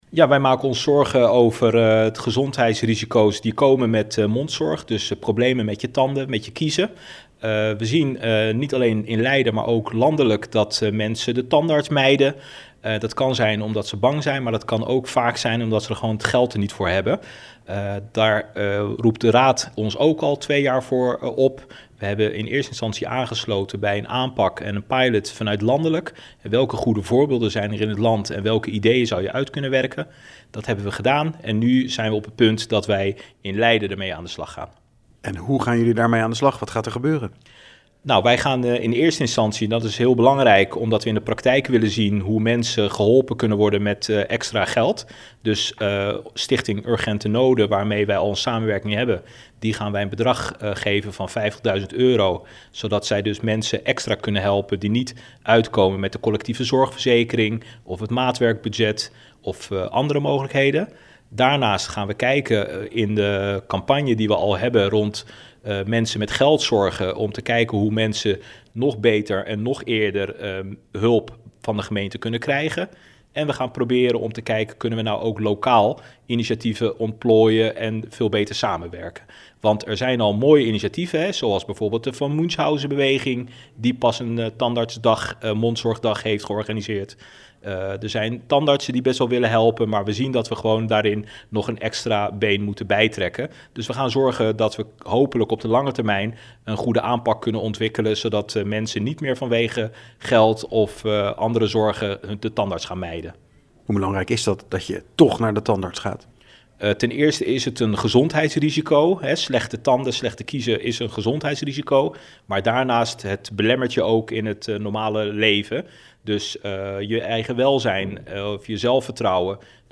Wethouder Abdelhaq Jermoumi over de aanpak mondzorg bij laagste inkomens: